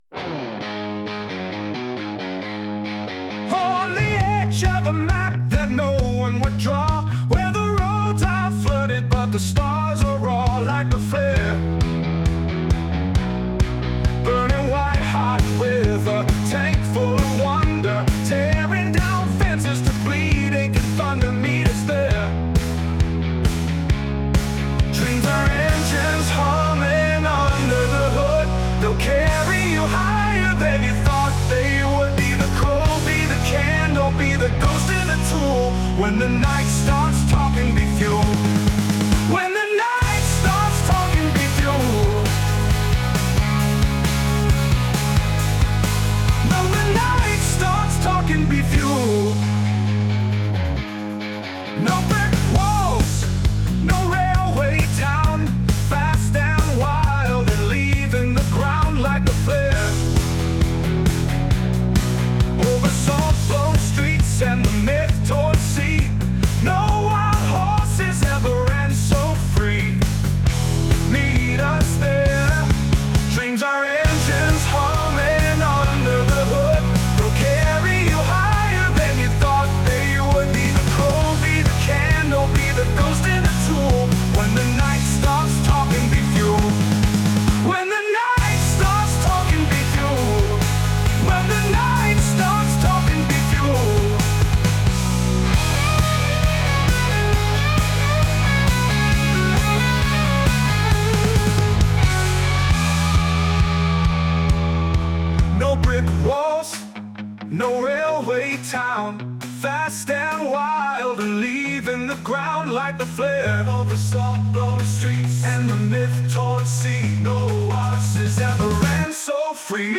I was going for Springsteen/Bob Dylan-style poetry with a modern rock vibe, and I wanted to include a variation on our slogan: “Dreams are engines.
And no, that isn’t me singing. I used Suno, an AI app, to take the lyrics and generate a bunch of variations – all in a rock & roll direction but with some tweaks on other styles to get something nice that didn’t sound like everyone else.
And if you liked the song, I’d especially appreciate hearing that as my wife thinks it’s too loud and fast.